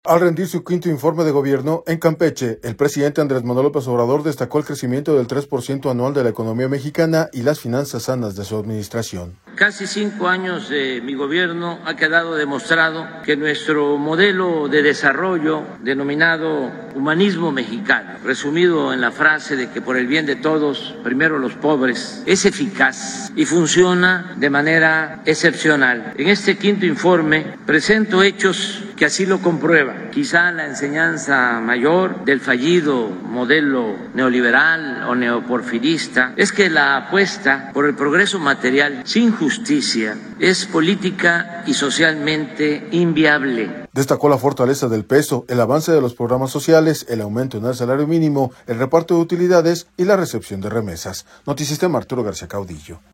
Al rendir su Quinto Informe de Gobierno, en Campeche, el presidente Andrés Manuel López Obrador, destacó el crecimiento del 3 por ciento anual de la economía mexicana y las finanzas sanas de su administración.